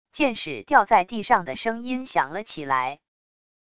箭矢掉在地上的声音响了起来wav音频